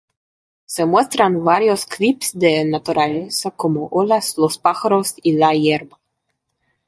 pá‧ja‧ros
/ˈpaxaɾos/